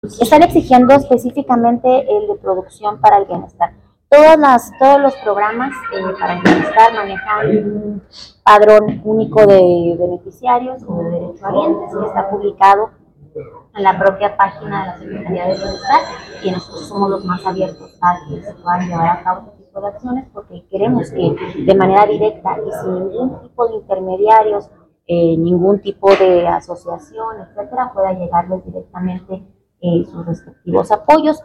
AUDIO: MAYRA CHÁVEZ, DELEGADA EN CHIHUAHUA D LA SECRETARÍA DE BIENESTAR 13